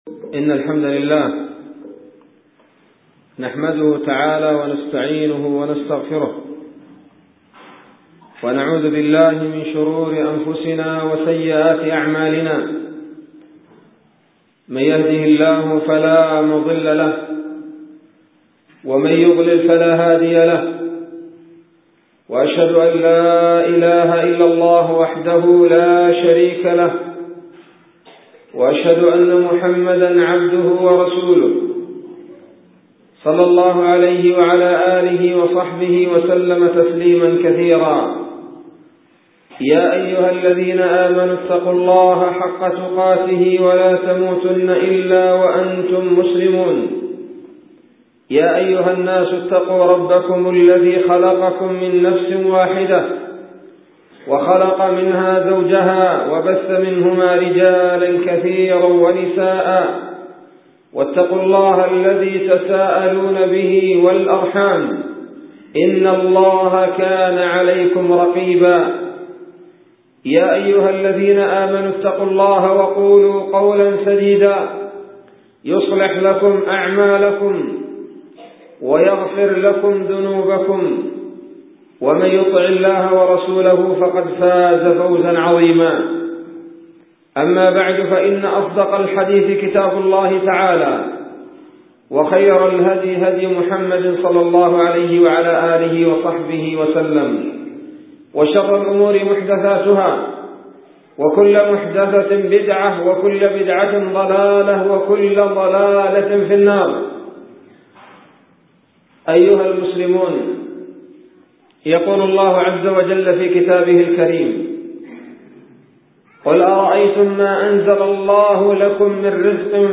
خطبة بعنوان : ((الرزق الحرام))